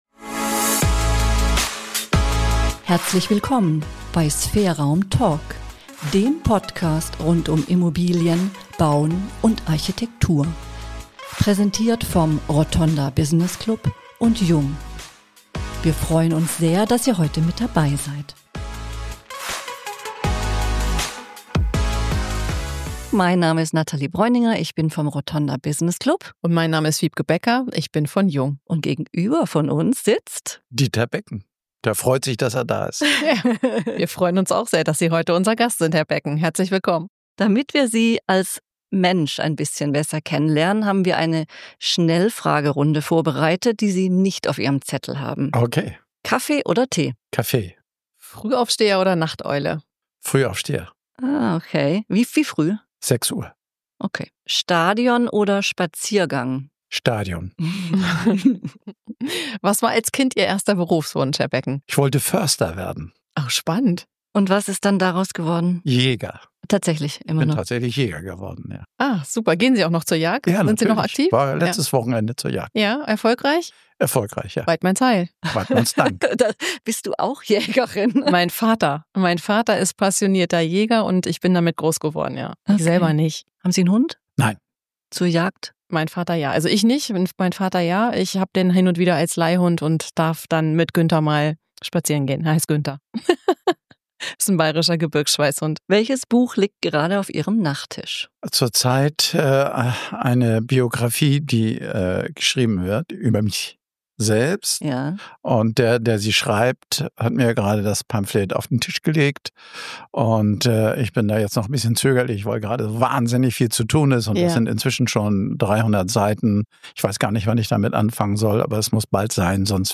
In unserem Gespräch erzählt er, wie er Risiken erkennt und auch mal auf ein vermeintlich gutes Geschäft verzichtet.